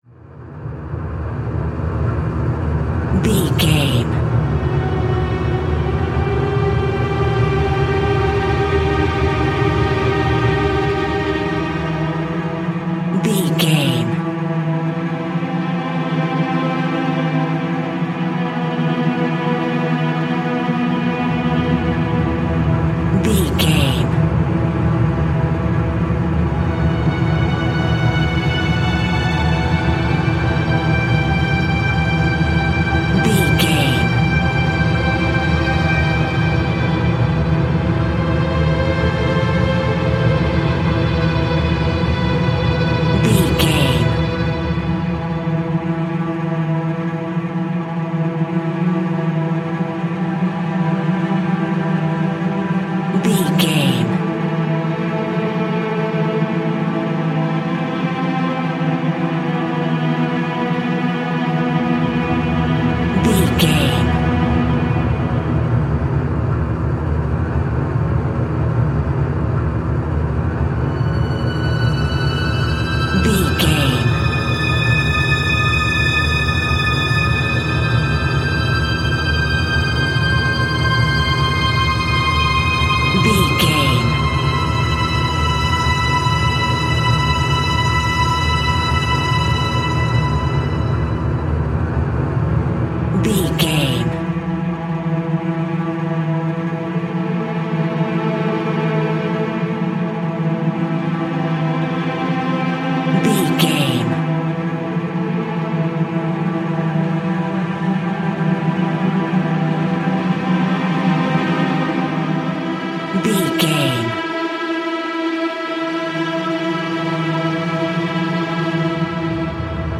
Aeolian/Minor
tension
ominous
suspense
haunting
eerie
strings